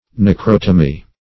Search Result for " necrotomy" : The Collaborative International Dictionary of English v.0.48: Necrotomy \Nec*rot"o*my\, n. [Gr. nekro`s dead person + te`mnein to cut.]